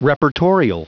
Prononciation du mot reportorial en anglais (fichier audio)
Prononciation du mot : reportorial